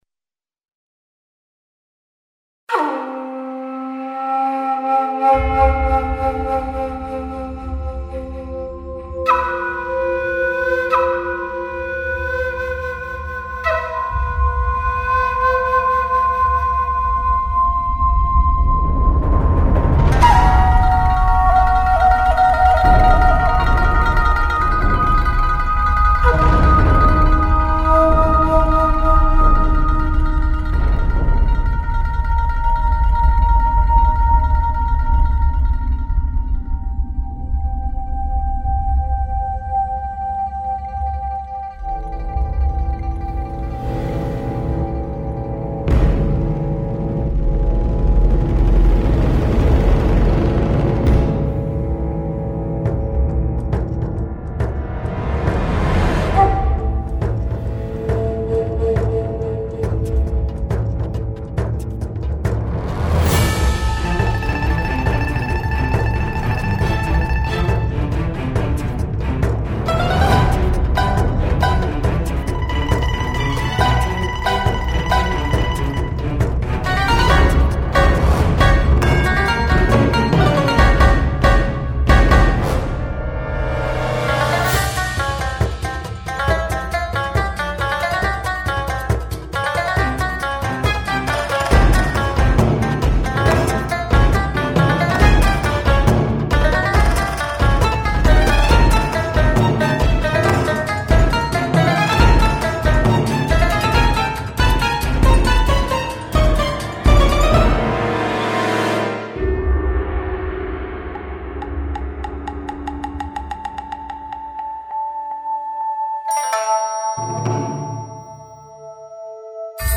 编曲和民乐的运用颇有新意，充分考虑了作为背景音乐的功能性和情绪性。编曲的细节略草率，尤其后半段。混音欠佳。
混音各项指标都很好；声音力度释放适中；但感觉声音偏紧；声部的空间效果一般；作曲给了高分。